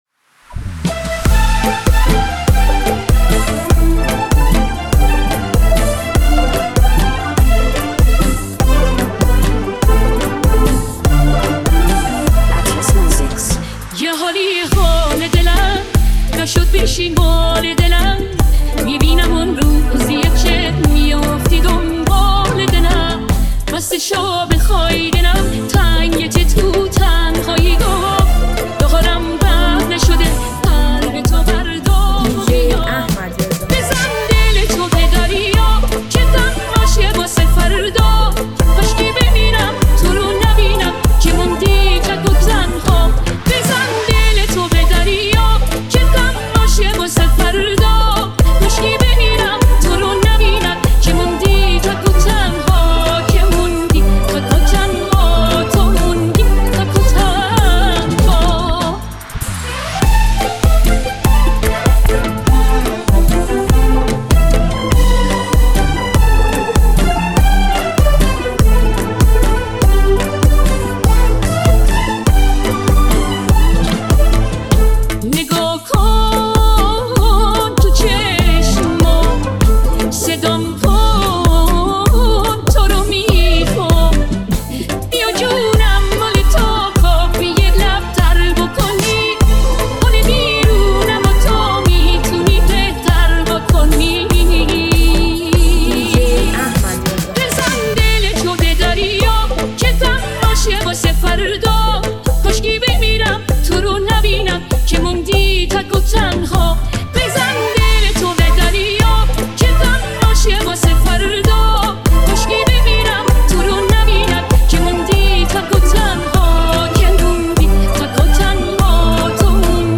ژانر: پاپ / رپ
ریمیکس شاد